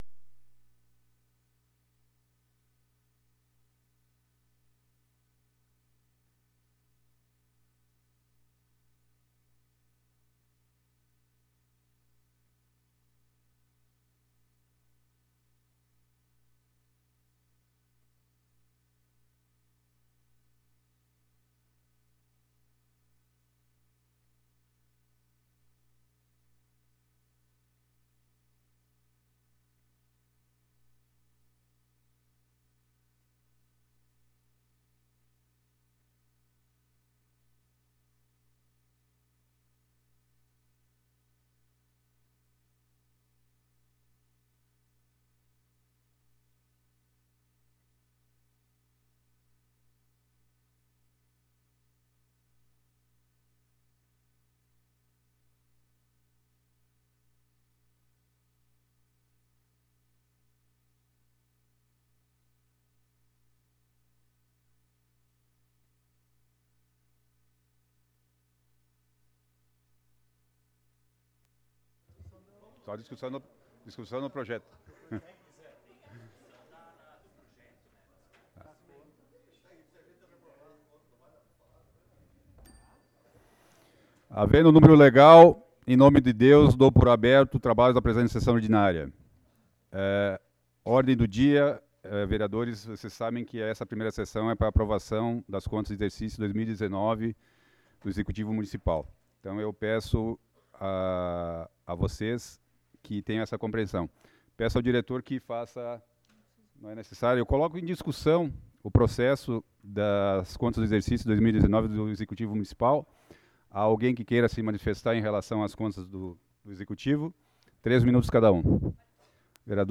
Sessão Ordinária do dia 27 de Junho de 2022 - Sessão 19